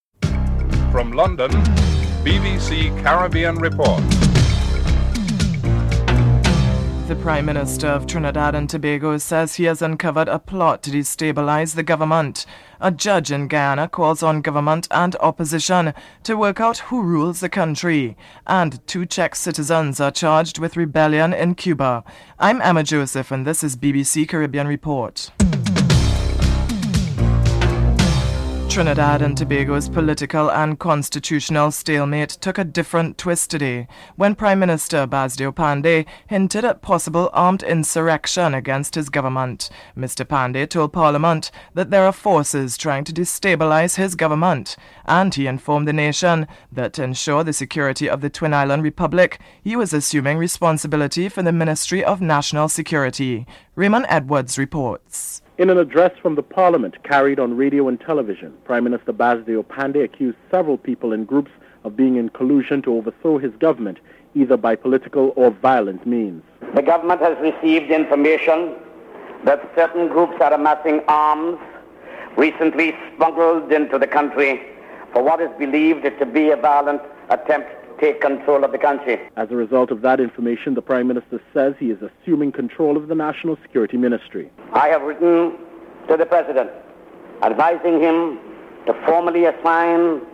Prime Minister Basdeo Panday and Opposition Leader Patrick Manning are interviewed.
Presiddent Bill Clinton is interviewed (12:13-15:24)